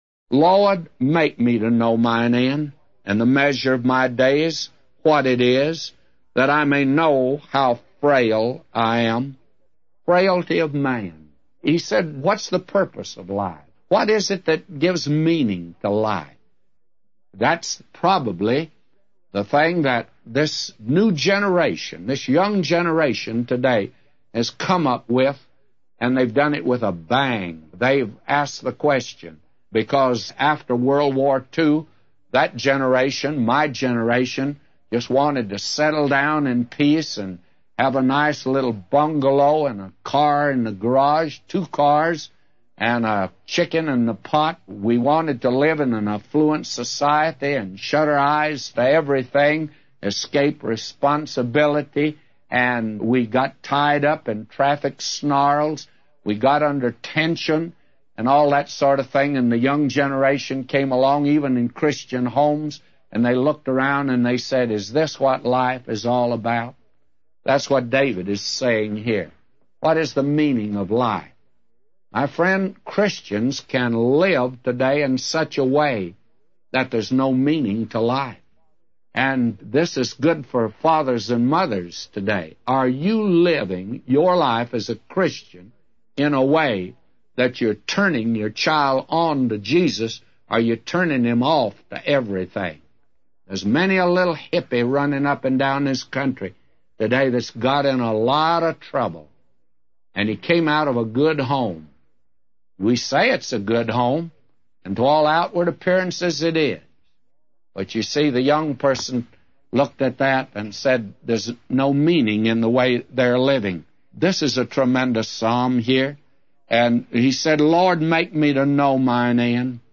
A Commentary By J Vernon MCgee For Psalms 39:4-999